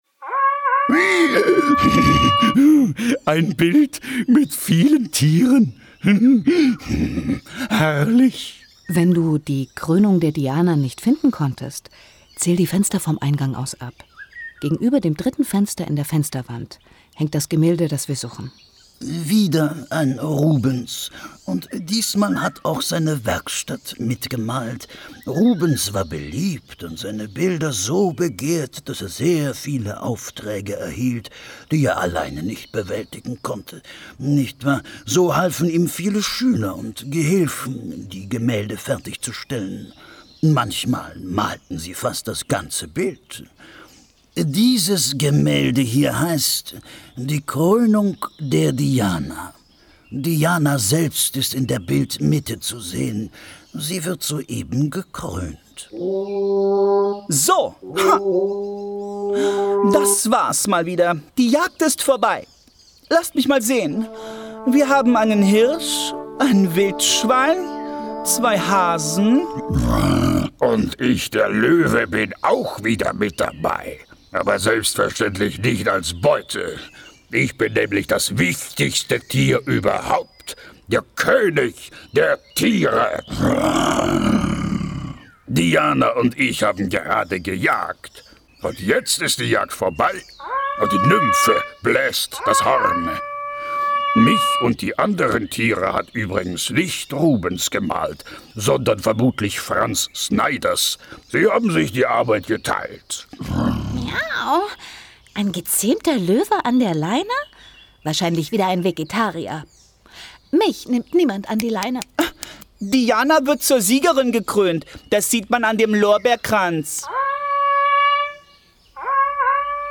In der Bildergalerie treffen Jugendliche ab elf Jahren auf eine sprechende Katze und den Galerieinspektor Matthias Oesterreich (1716-1778).